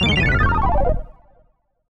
redpandadies.wav